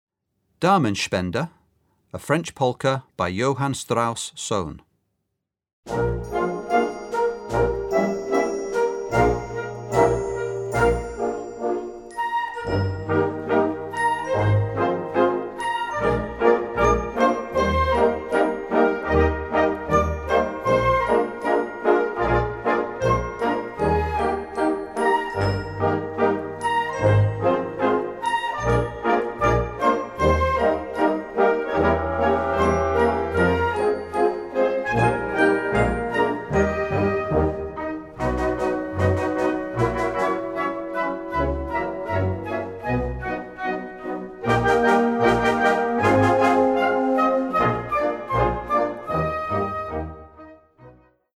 Gattung: Polka française
Besetzung: Blasorchester